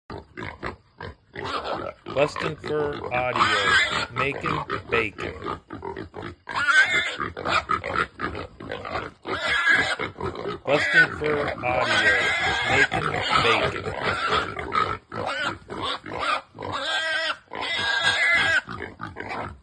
Makin Bacon is a tested and proven sound of hogs feeding and fighting that can be used to call hogs into gun range!